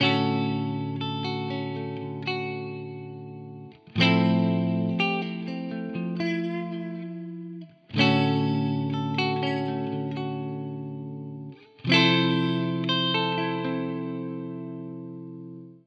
Fender style Amp clean:
Fender style Amp
fenderduosoniccleanfenderstyleamp.wav